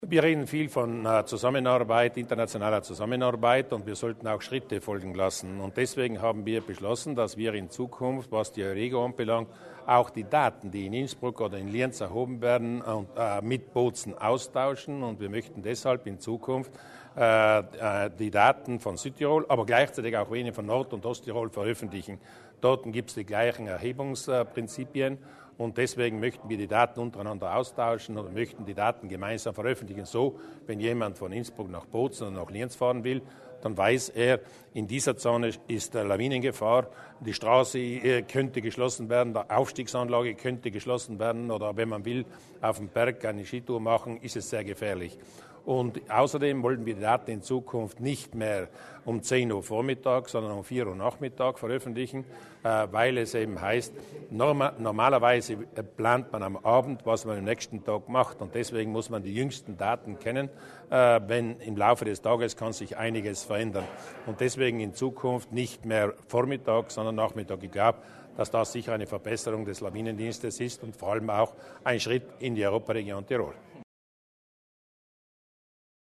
Landeshauptmann Durnwalder erklärt die Neuheiten im Lawinenwarndienst